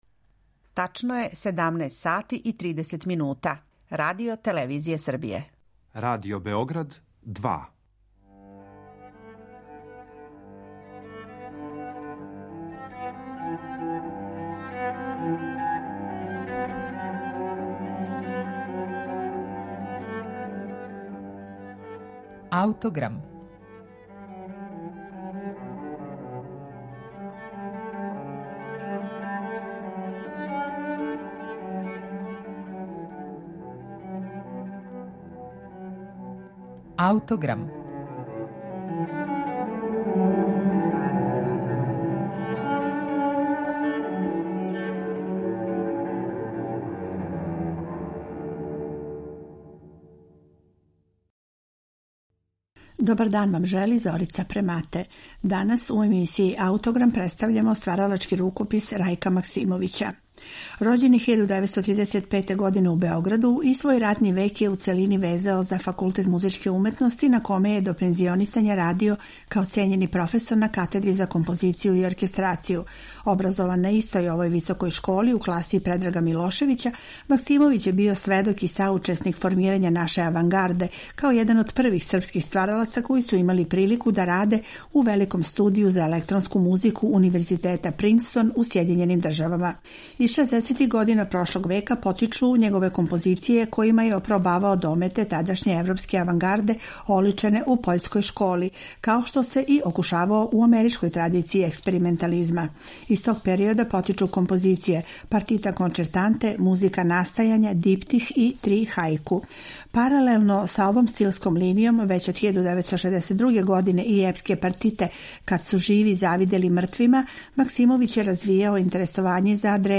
за гудачки оркестар